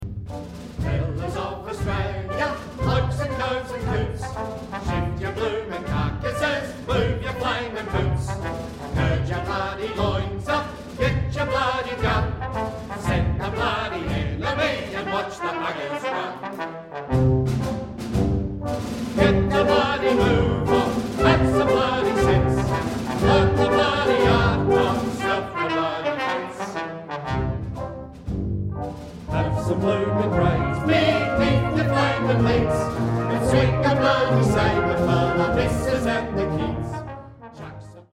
Australian classical music